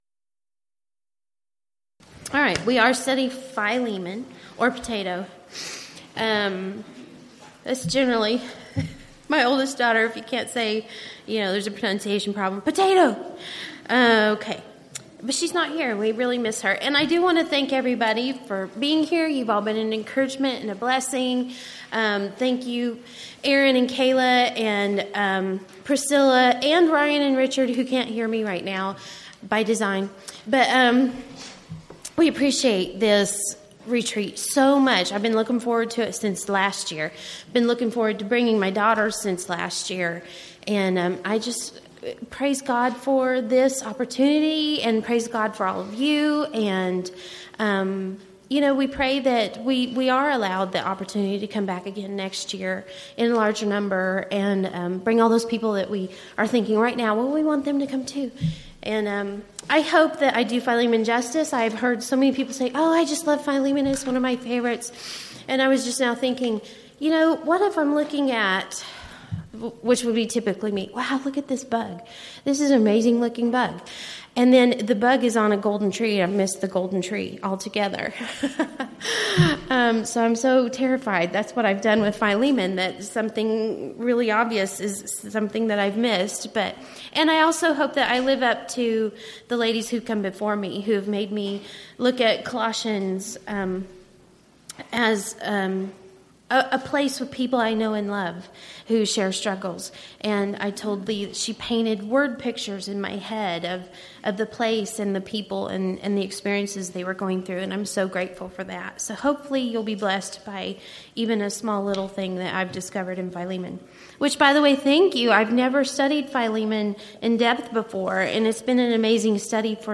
Event: 2014 Texas Ladies in Christ Retreat
Ladies Sessions